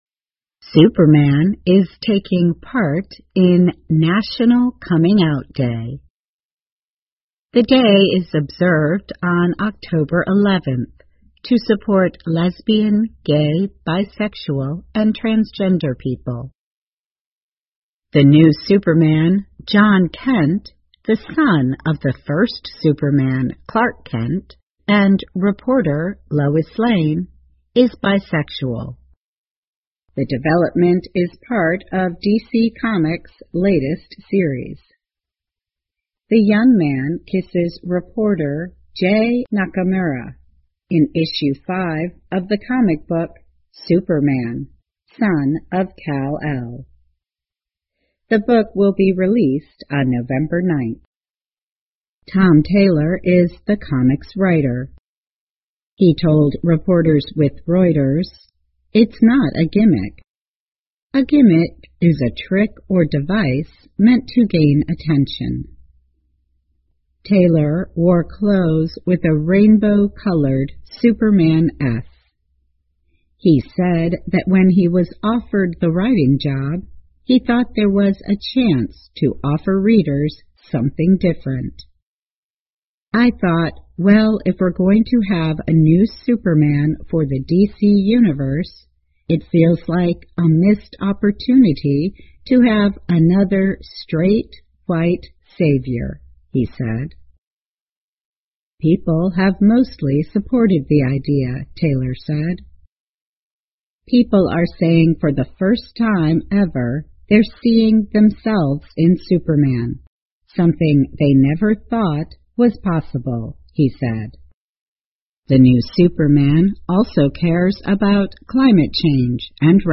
VOA慢速英语2021 DC漫画新超人是双性恋 听力文件下载—在线英语听力室